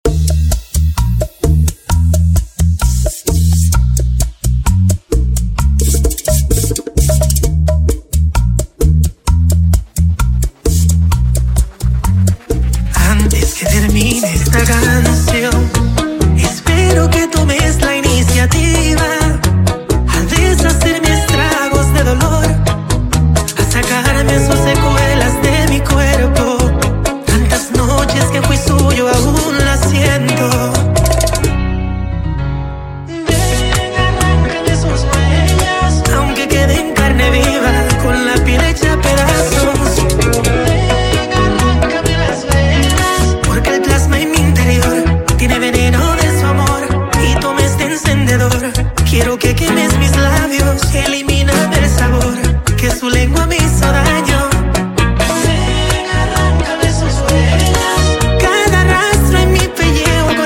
BACHATA INTRO BPM 130